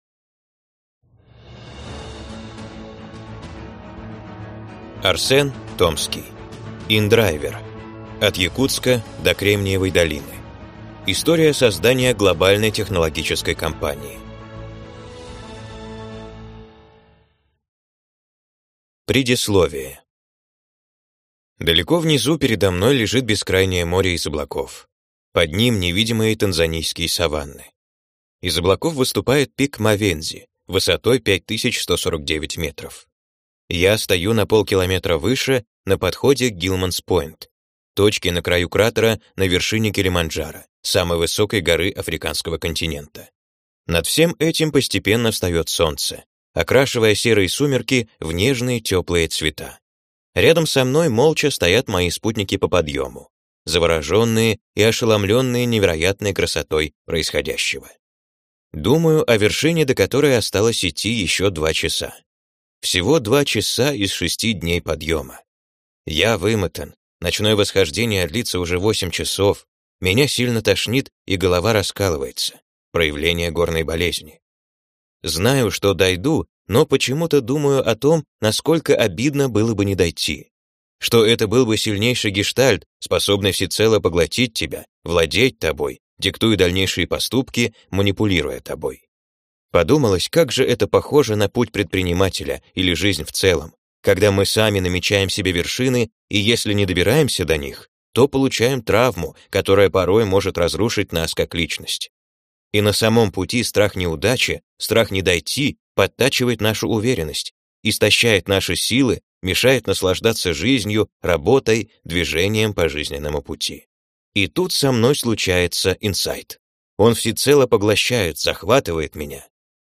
Аудиокнига InDriver: От Якутска до Кремниевой долины | Библиотека аудиокниг